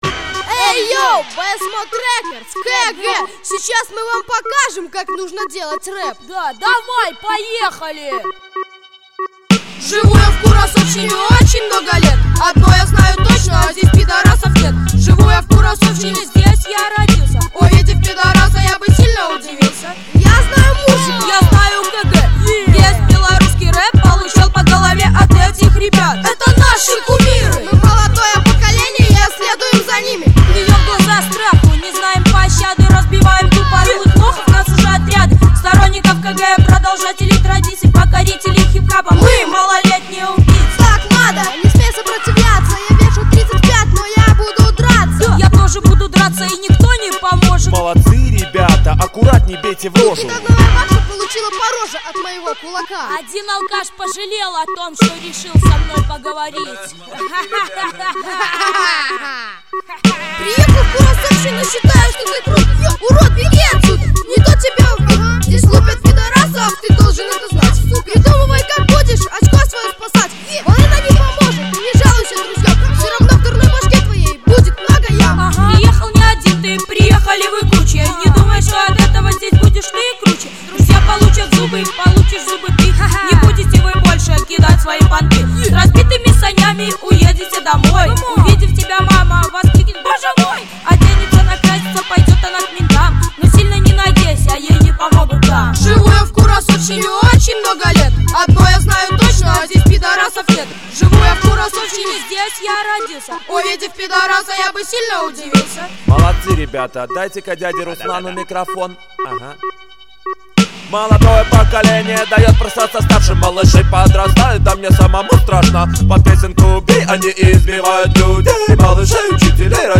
• Жанр: Хип-хоп
12-ти летние малыши